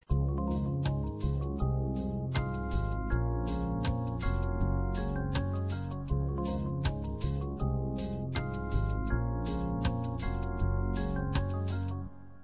ritmica dell’organo Hammond